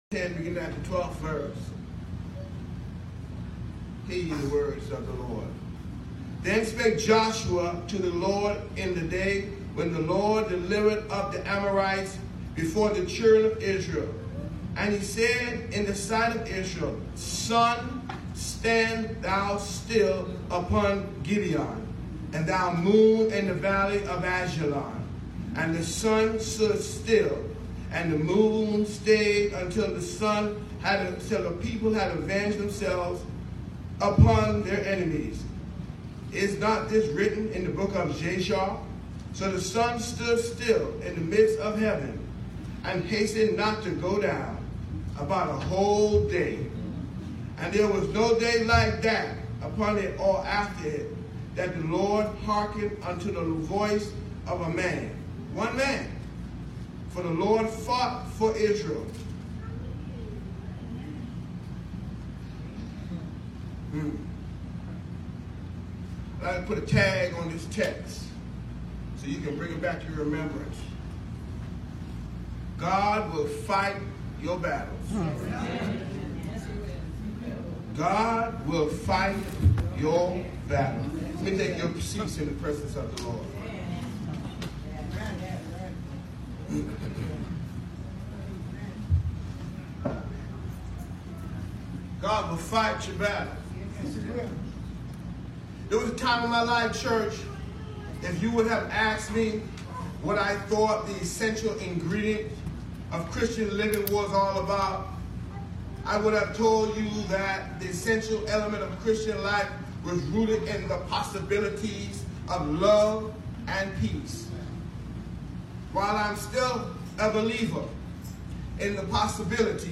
JUNE 23, 2019 SUNDAY 11:00am New Jerusalem MB Church Joshua 10:12-14 The Message: “GOD WILL FIGHT FOR YOU”